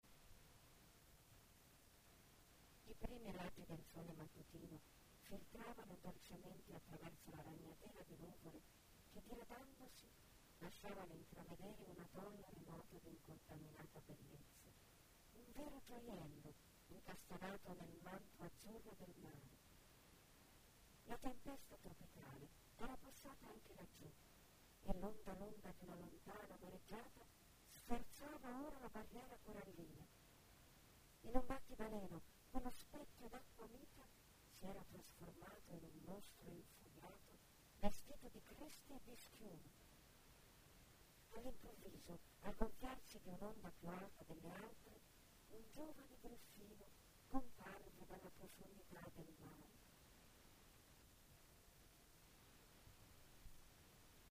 Kein Dialekt
Sprechprobe: Industrie (Muttersprache):